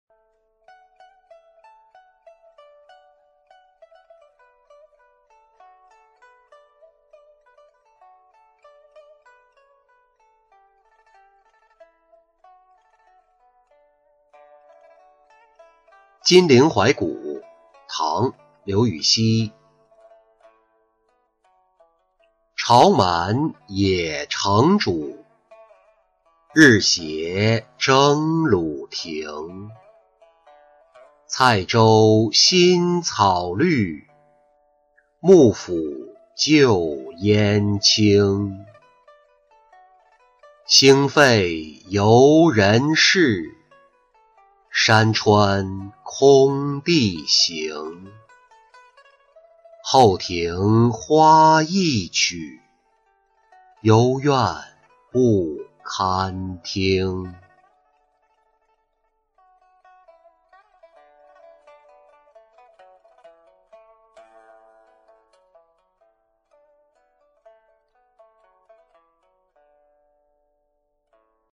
金陵怀古-音频朗读